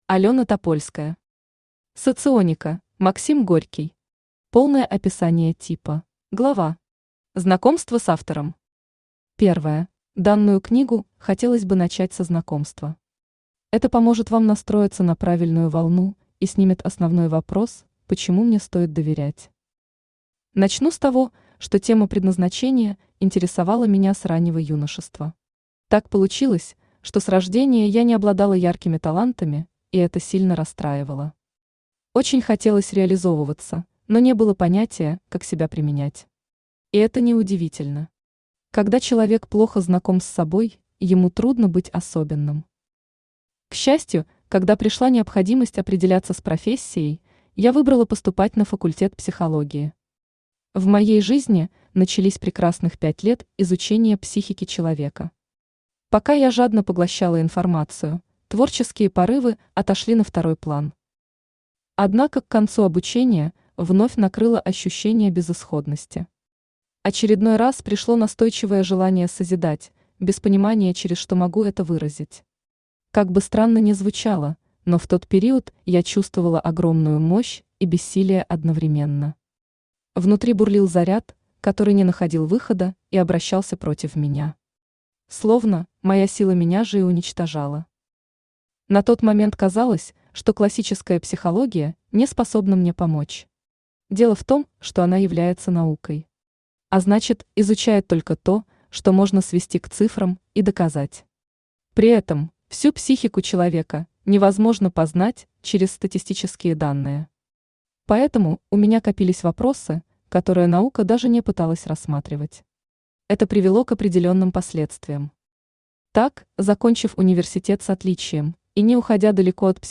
Аудиокнига Соционика: «Максим Горький». Полное описание типа | Библиотека аудиокниг
Читает аудиокнигу Авточтец ЛитРес.